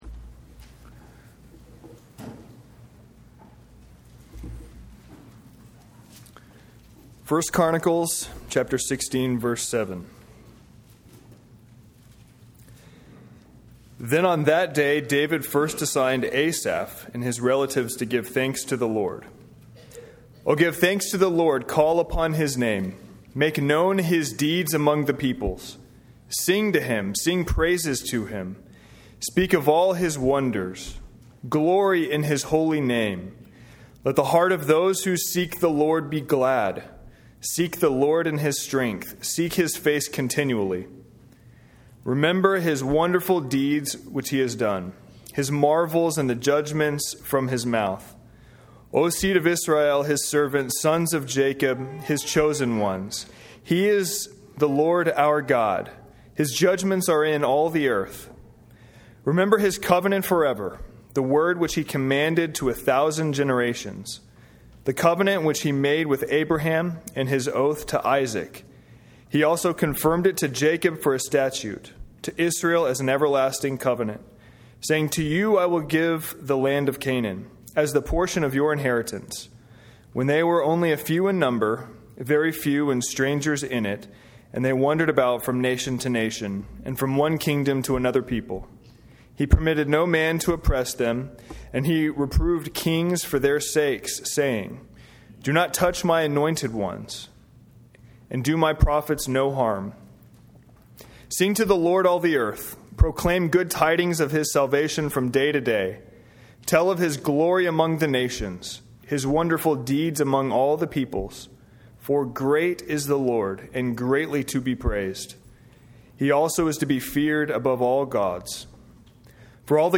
From Series: "Seasonal Sermons"